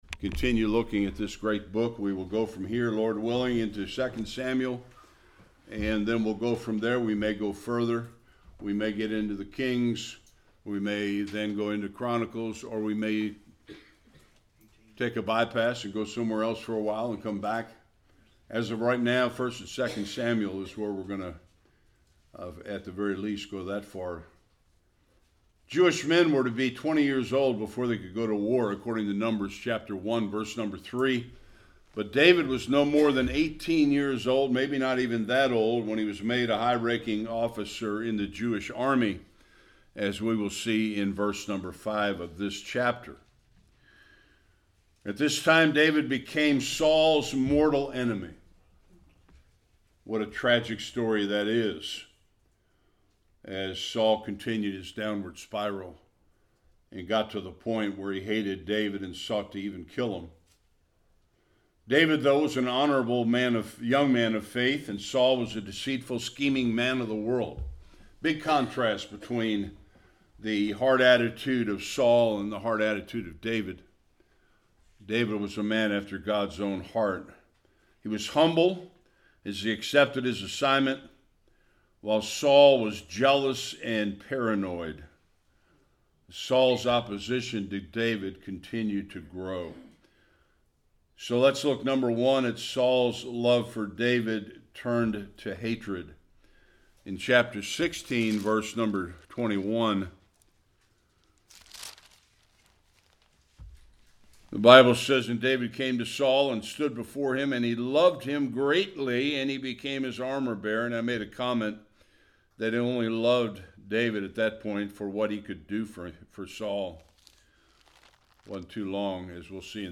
1-30 Service Type: Sunday School King Saul continues to lose it over David.